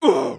damage_3.wav